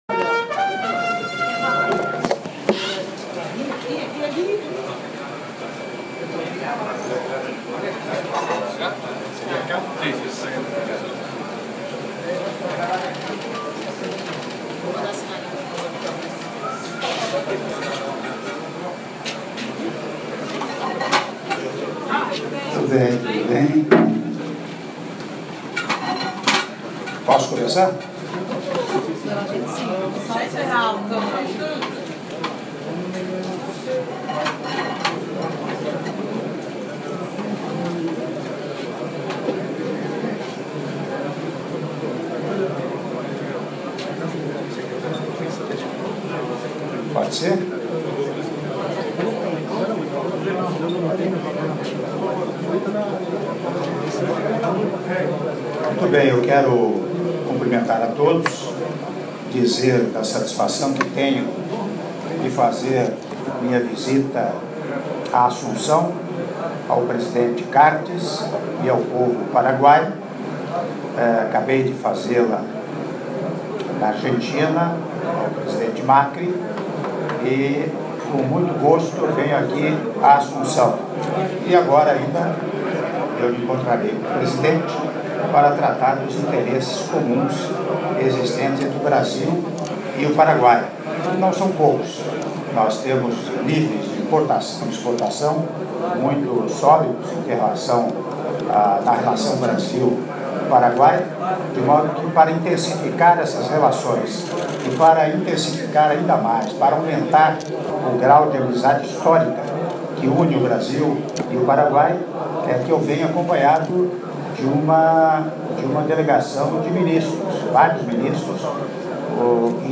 Áudio da declaração à imprensa do presidente da República, Michel Temer, na chegada a Assunção - Assunção/Paraguai (04min50s)
Entrevista na chegada ao Paraguai